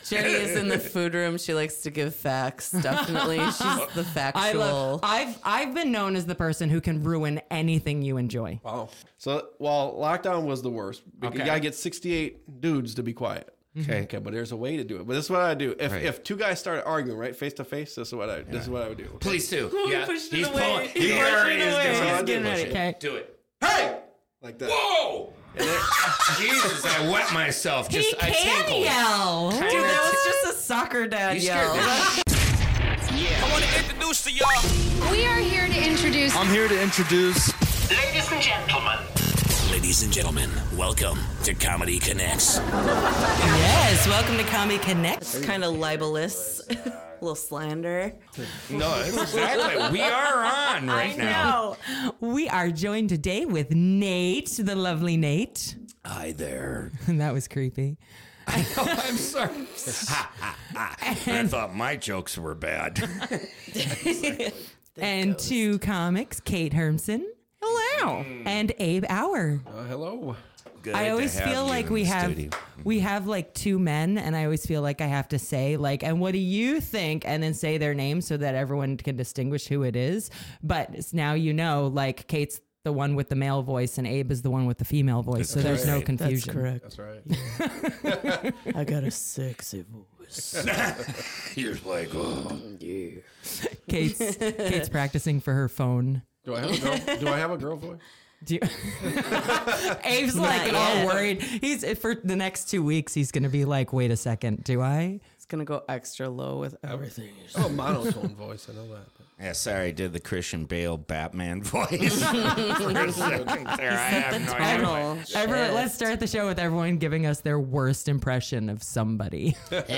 Join us as we chat with a variety of comedians to give you a behind the scenes look at the lives of comedians, how they hone their craft, and hilarious quips along the way.
Be a guest on this podcast Language: en Genres: Comedy , Comedy Interviews , Stand-Up Contact email: Get it Feed URL: Get it iTunes ID: Get it Get all podcast data Listen Now... Sportsball